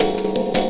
Percussion 09.wav